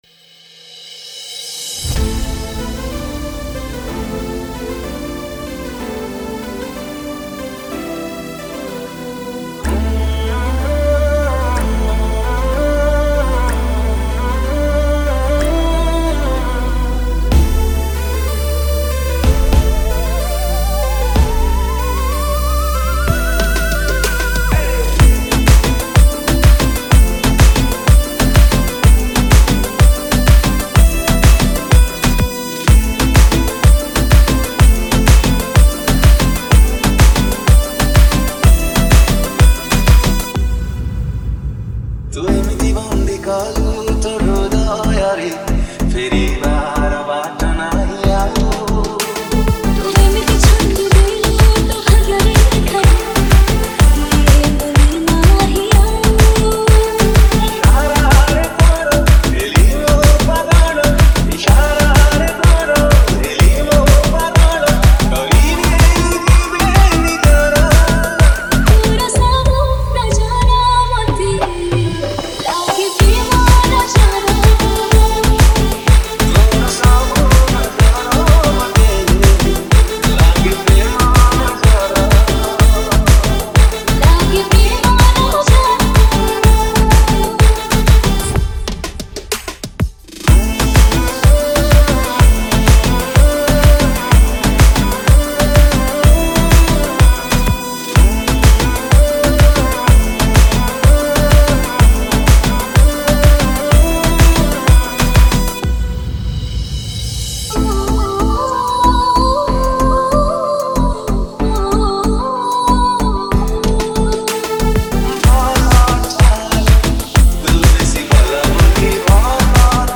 Romantic Love Dj Remix Songs Download
Romantic Love Dj Remix